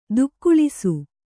♪ dukkuḷisu